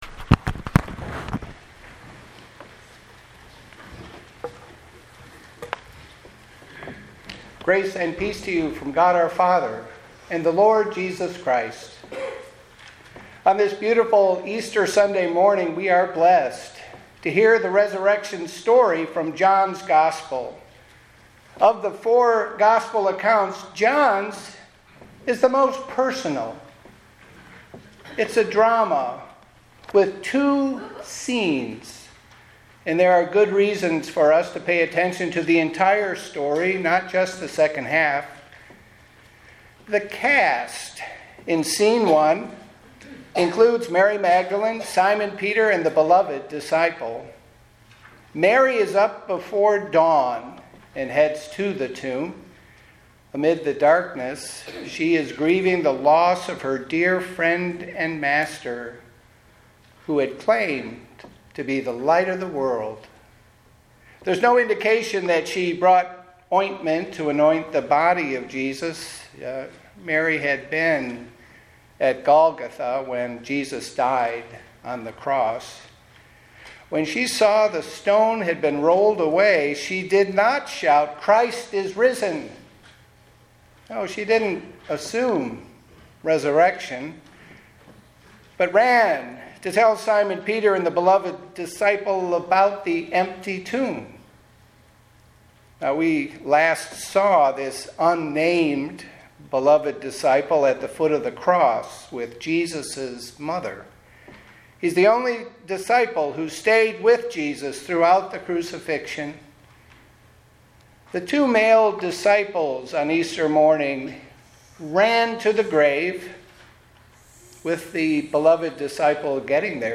Pastor's sermon is based on John 20:1-18.
Easter Sunday Sermon.MP3